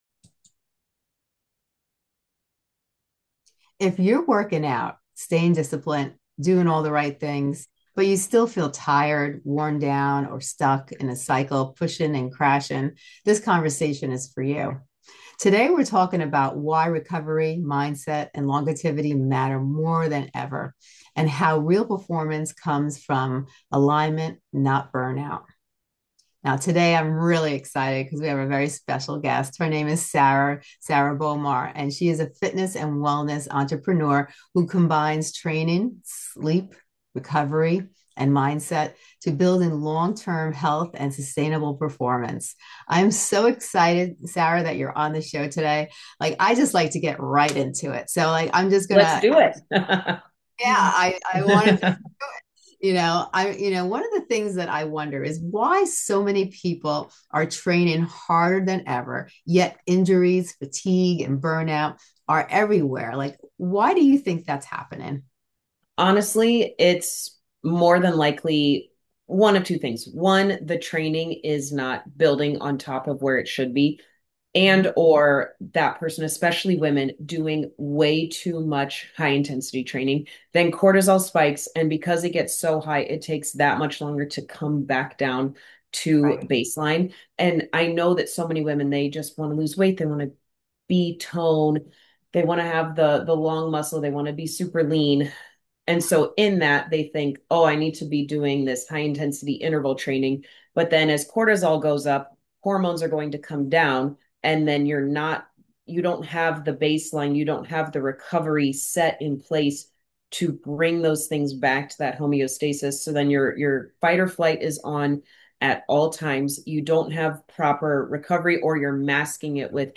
This is a practical, grounding conversation designed to help you train smarter, feel better, and last longer—starting now.